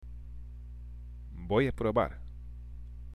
（ボイア　プロバール）